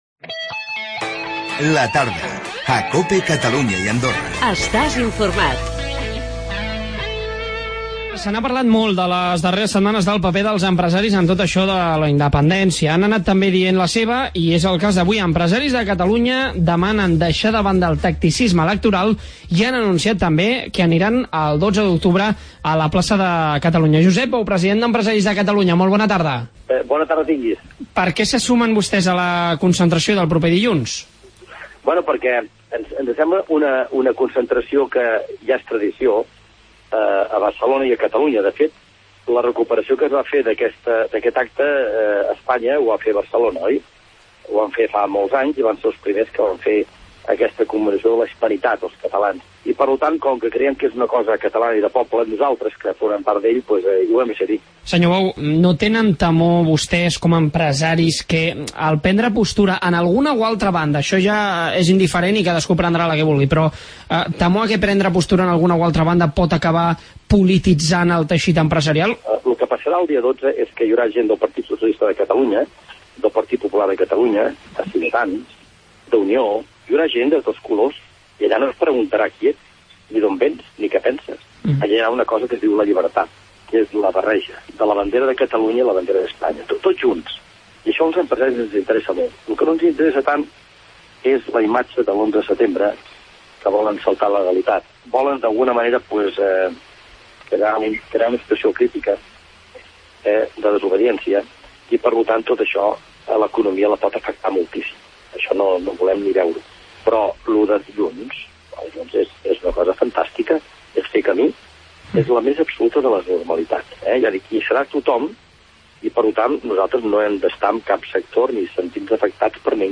AUDIO: Empresaris catalans participaràn el 12O. Avui ens ho ha explicat en Josep Bou, president d'empresaris de Catalunya